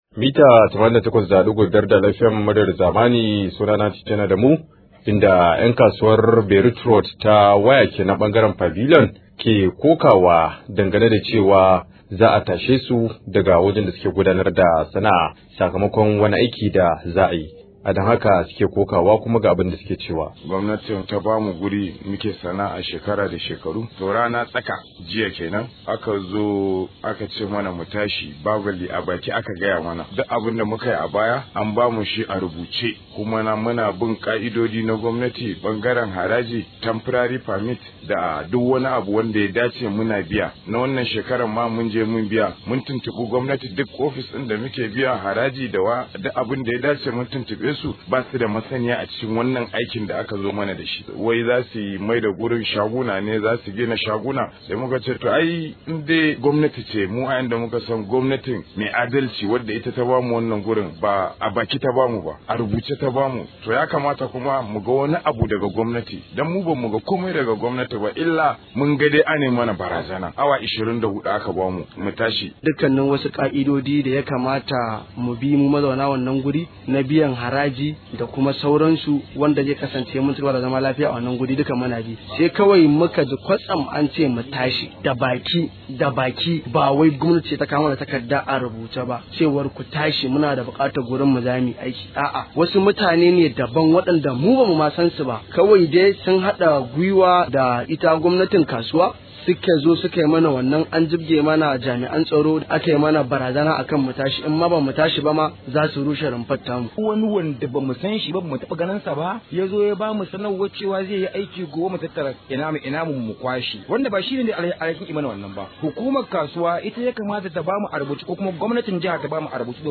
Rahoto: Kafin tashin mu a fara duba mana makomar mu – Ƴan Kasuwar Beirut